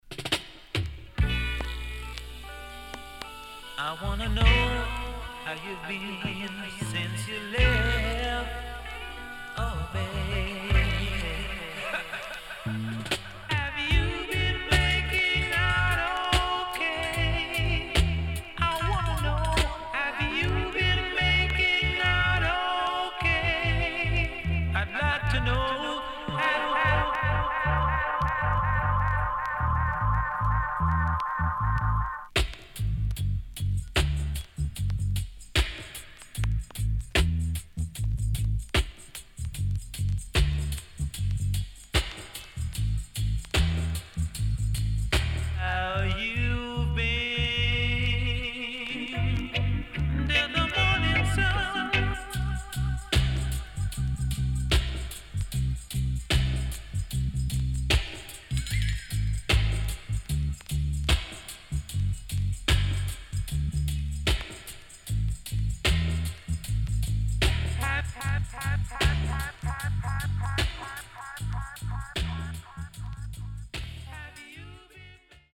Great Vocal.後半Deejay接続
SIDE A:所々チリノイズがあり、少しプチノイズ入ります。